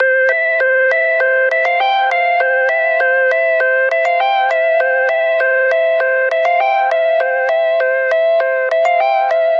Triphop /舞蹈/拍/嘻哈/毛刺跳/缓拍/寒意
Tag: 寒意 旅行 电子 舞蹈 looppack 样品 毛刺 节奏 节拍 低音 实验 器乐